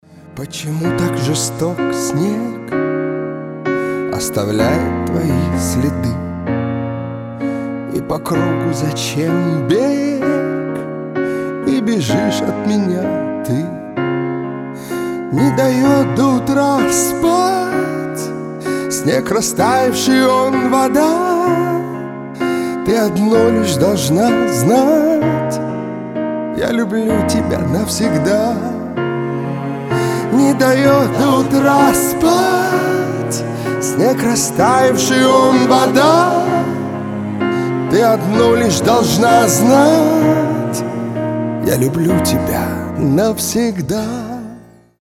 спокойные
пианино
виолончель